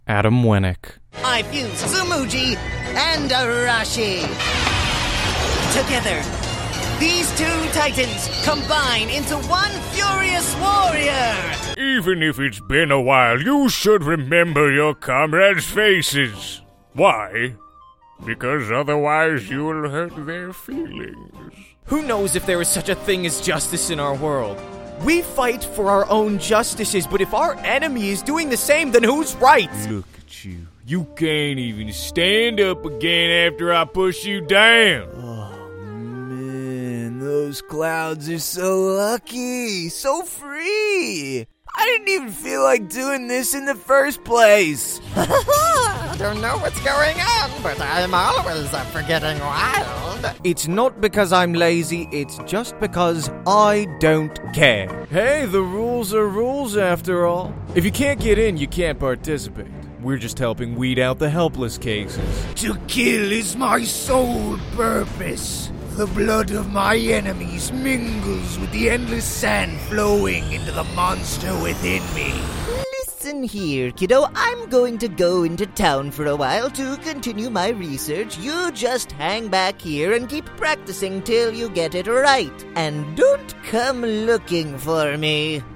Male
Teenager (13-17), Yng Adult (18-29)
I also have a wide range of character voices.
Character / Cartoon
My Range Of Characters
Words that describe my voice are Genuine, John Krasinski Type, Friendly.